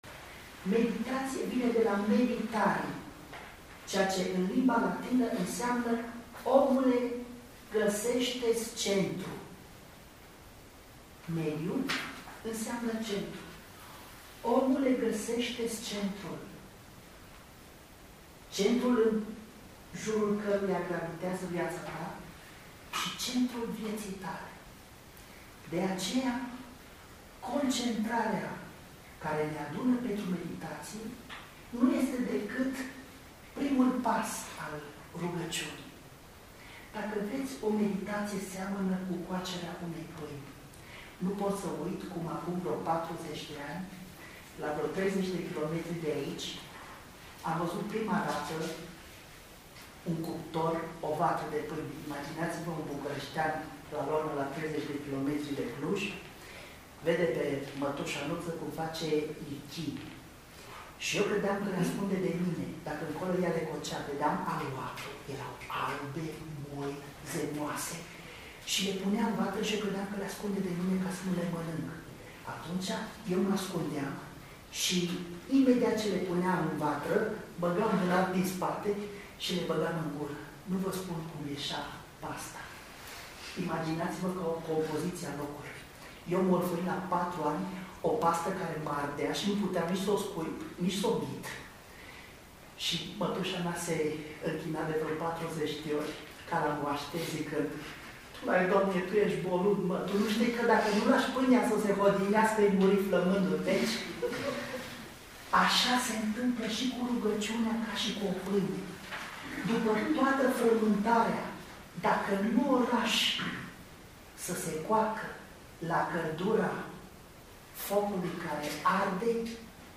O seară cu trei momente distincte: o oră de rugăciune în spirit ignațian, celebrarea Sfintei Liturghii și o mică agapă frățească.